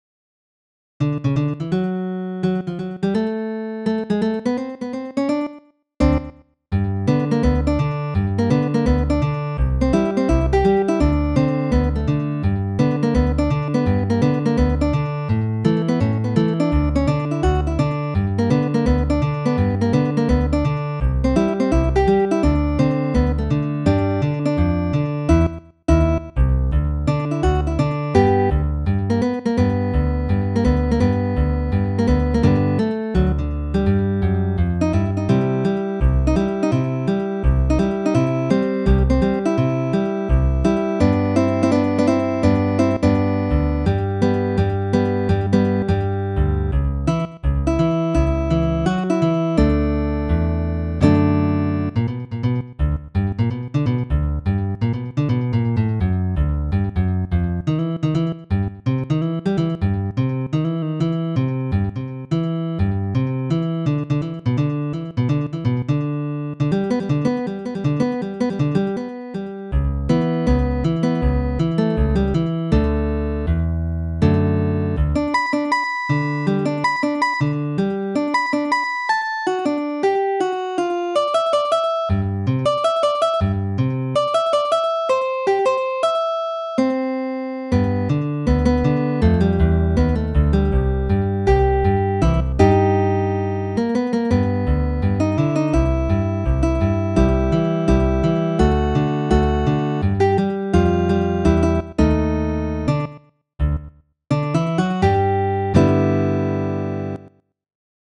as fingerstyle arrangements.